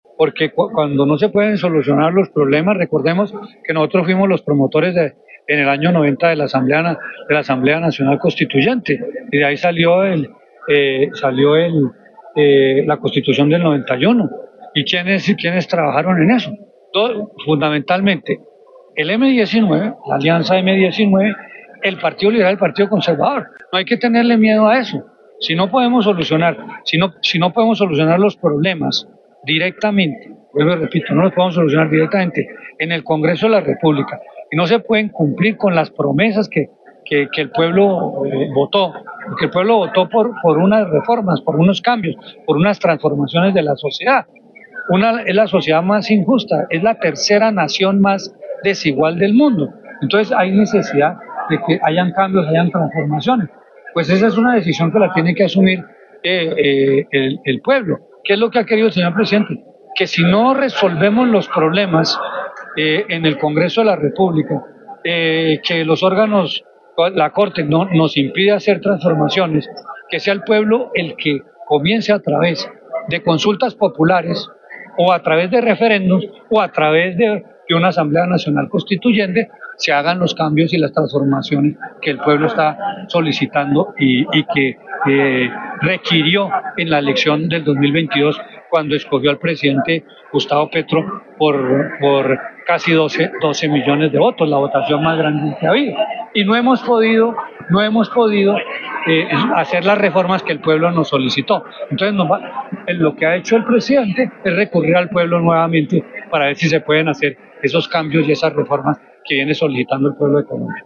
Guillermo Jaramillo, ministro de Salud